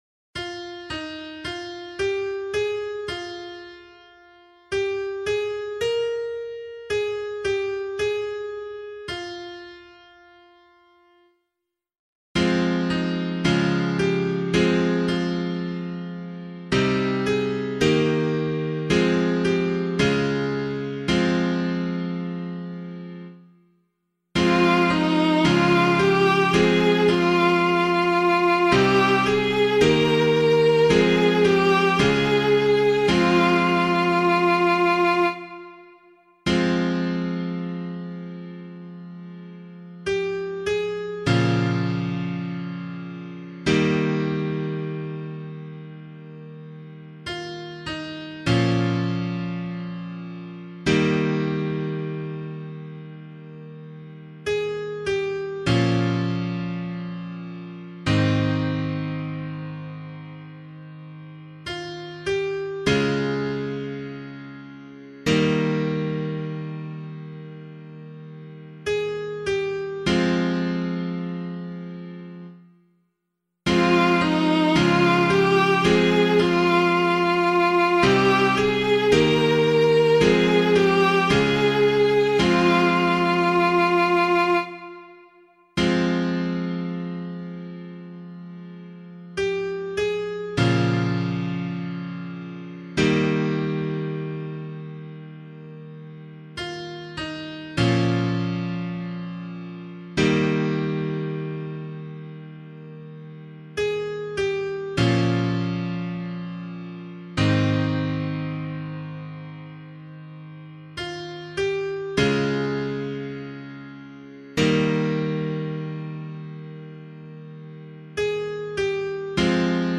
016 Lent 4 Psalm B [LiturgyShare 2 - Oz] - piano.mp3